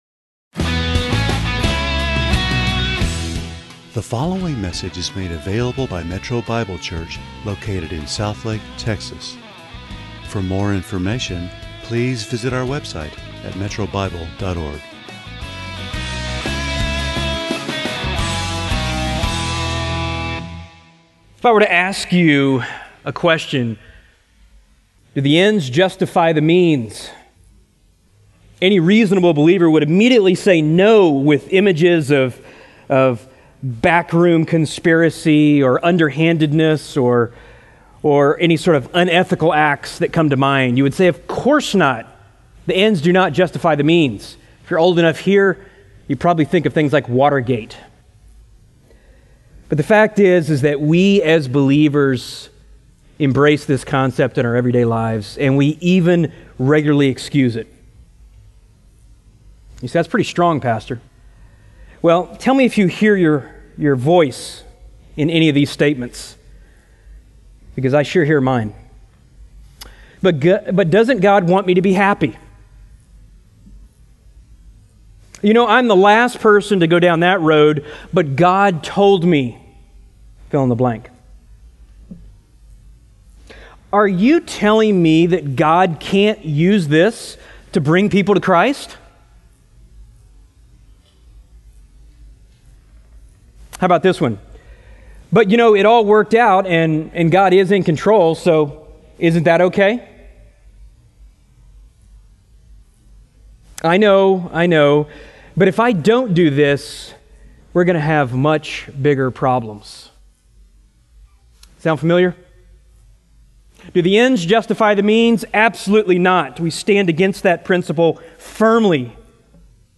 × Home About sermons Give Menu All Messages All Sermons By Book By Type By Series By Year By Book Do The Ends Justify The Means?